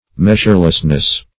Meas"ure*less*ness, n.